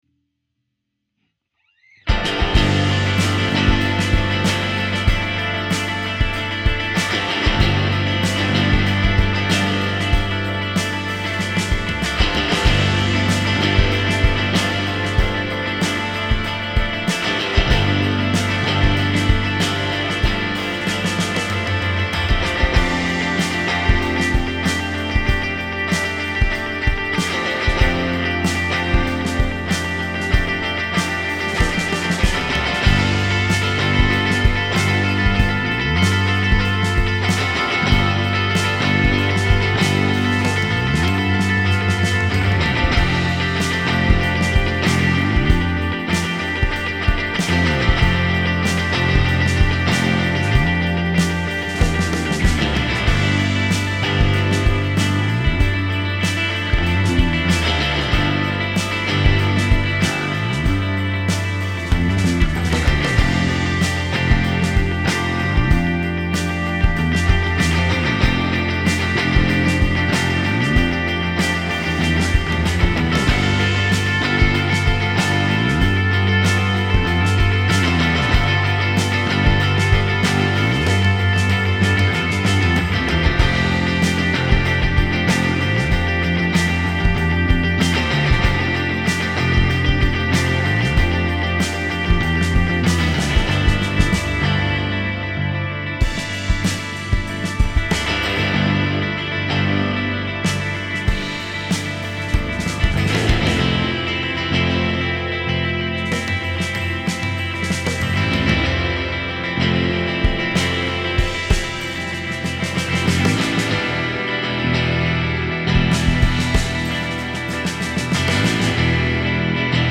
Strat Sounds 1/20/24, 20.15
Playing around with some new pickups.